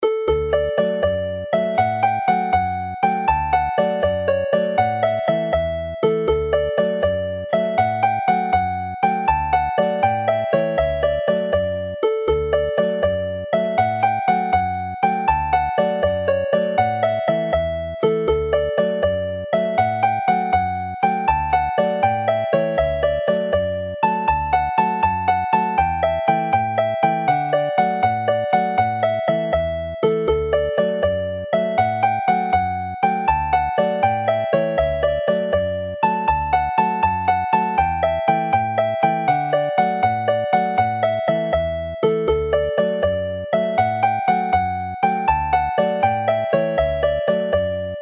This set of jigs
Play the melody slowly